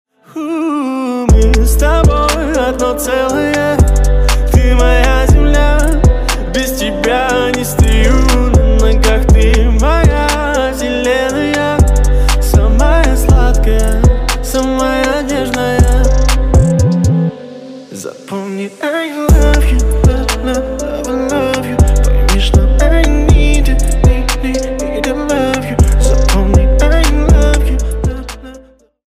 melodia ruseasca
Categorie: Hip-Hop